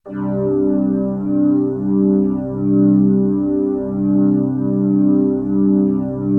CHRDPAD043-LR.wav